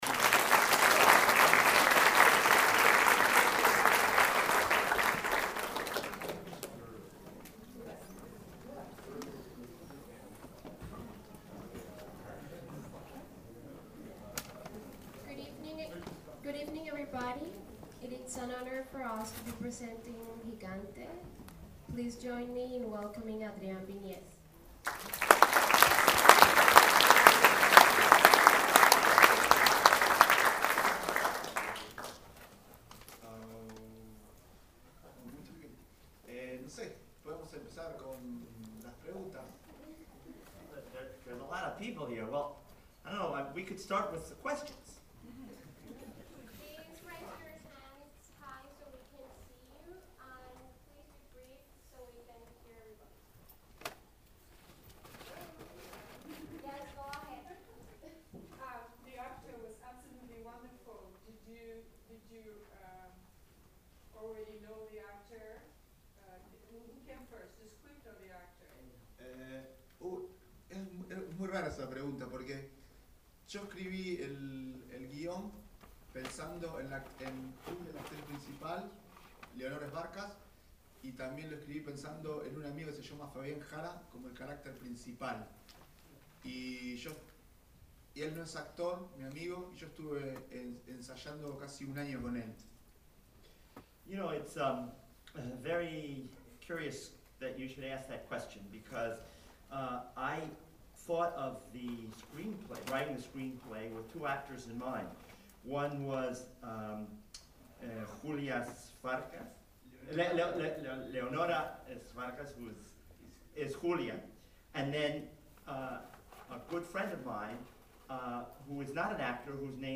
gigante_qa.mp3